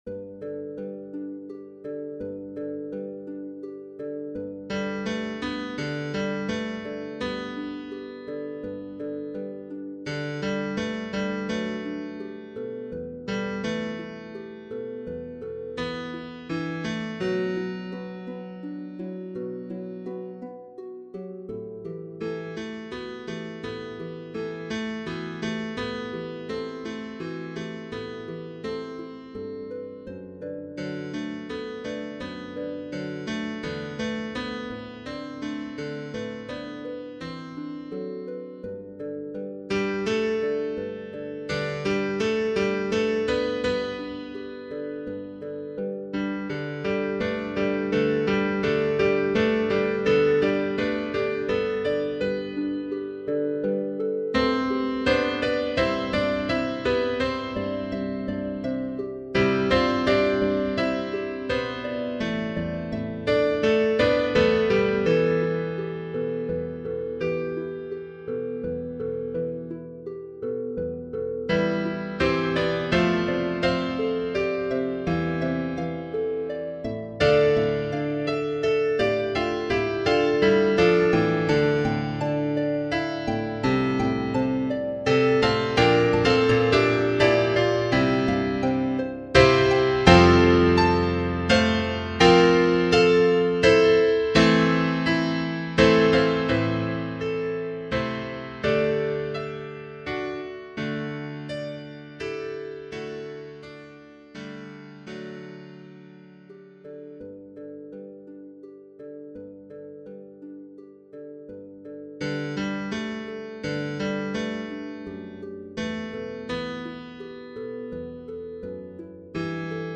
Tutti
The featured part is a horn or a bassoon.
The recordings begin softly and get louder.